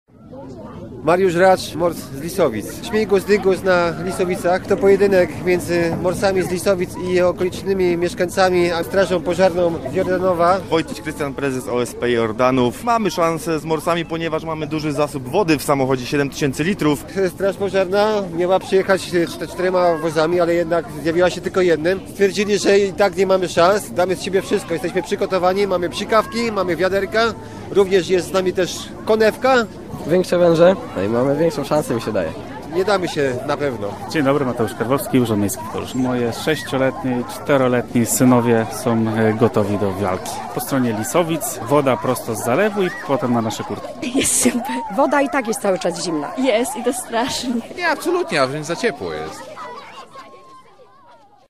Zgodnie z tradycją lanego poniedziałku, przy zalewie w Lisowicach koło Koluszek, odbyła się wodna "bitwa", w której udział wzięli okoliczni mieszkańcy, środowisko morsów i strażacy.